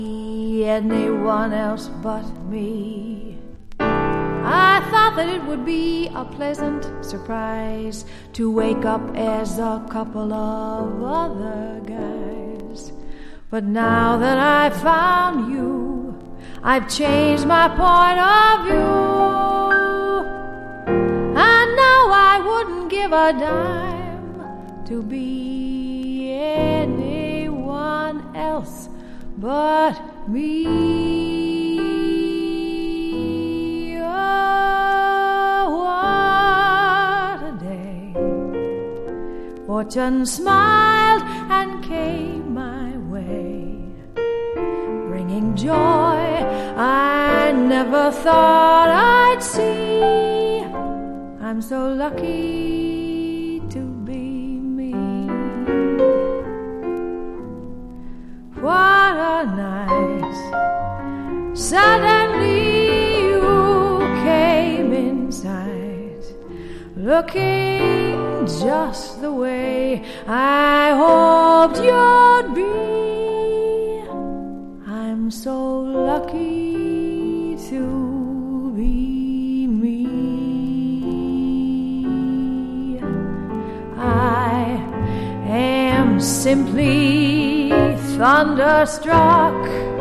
澄んでいて凛とした歌声も◎。